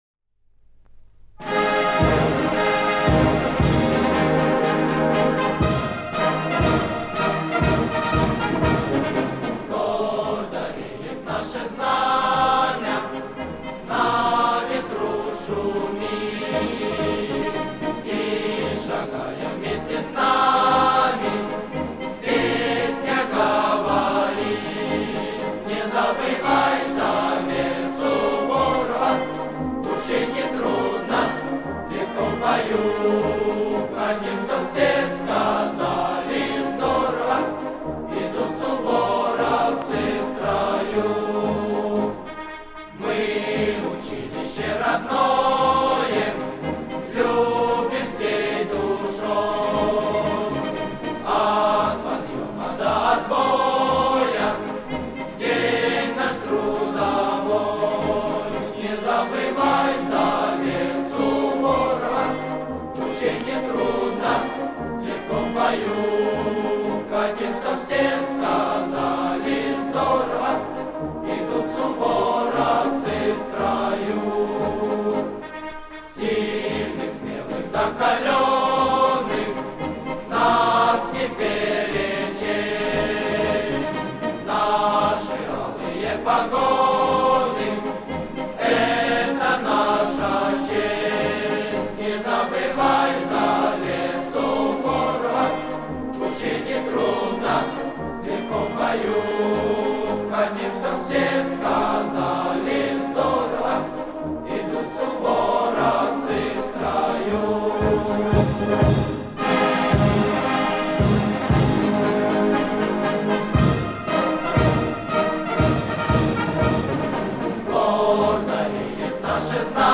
Марши
- яркая, запоминающаяся, с крепкой мелодией.